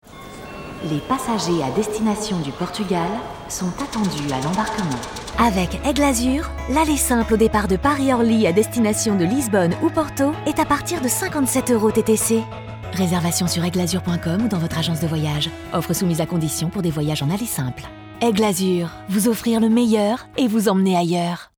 Voix off radio pour les publicités de la compagnie aérienne Aigle Azur